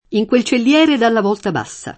volta [v0lta] s. f. («arco») — spesso con acc. scr. (vòlta, non vôlta), pur senza quel bisogno di distinguere che ci sarebbe in volto: In quel celliere dalla vòlta bassa [
ij kU%l ©ellL$re dalla v0lta b#SSa] (Pascoli); dipingeva sopra le vòlte della Sistina le origini e i tempi primissimi [